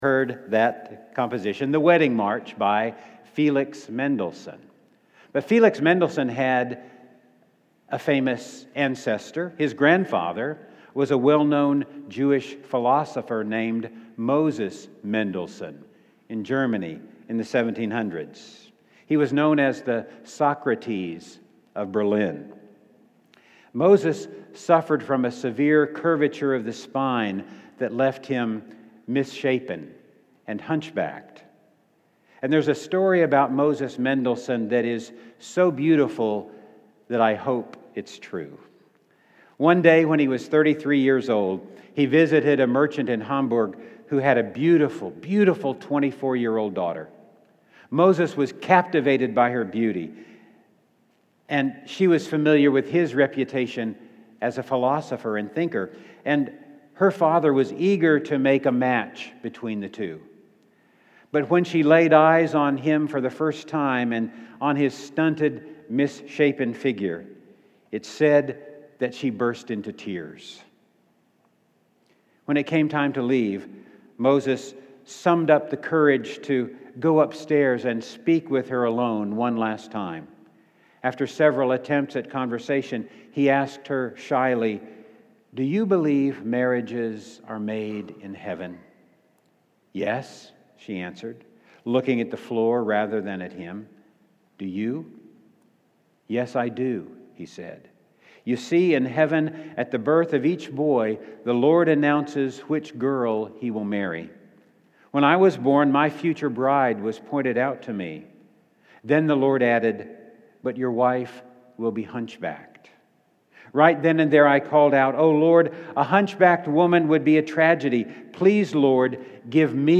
Sermons - Floral Heights United Methodist Church